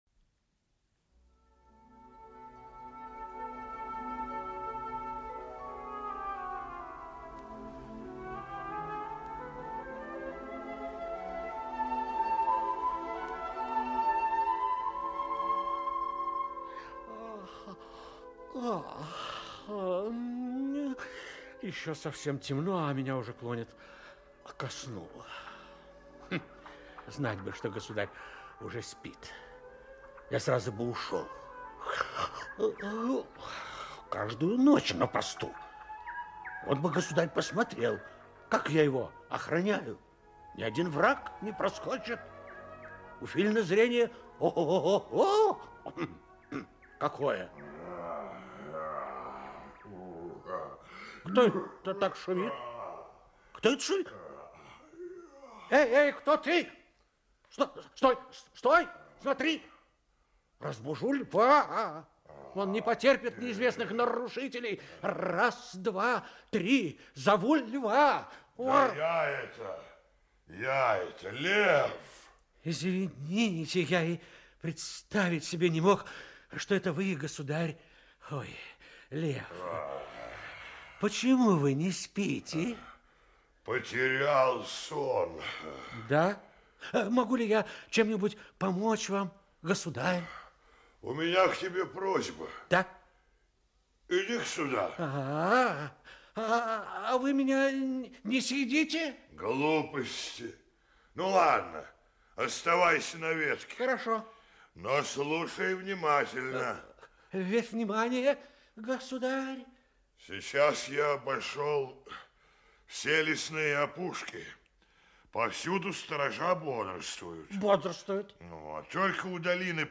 Аудиокнига Надоедливый Жужжалка | Библиотека аудиокниг
Aудиокнига Надоедливый Жужжалка Автор Дранки Читает аудиокнигу Актерский коллектив.